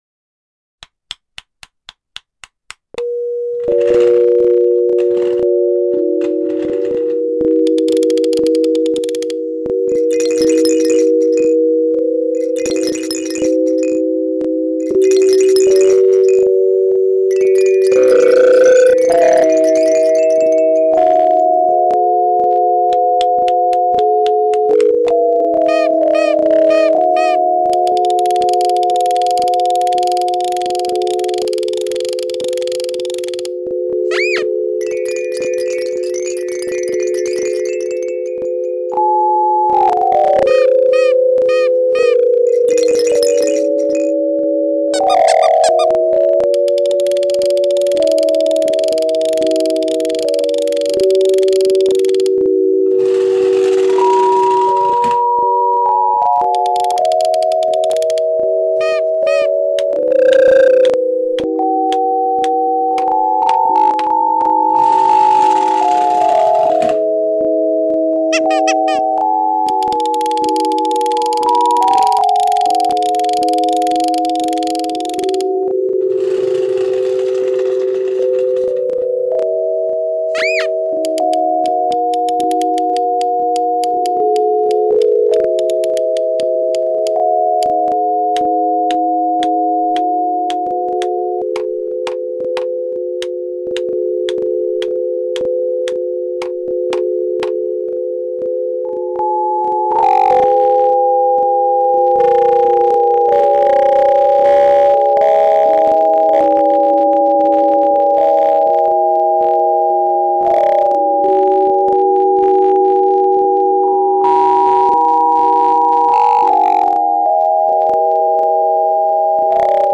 AI generated auditory artwork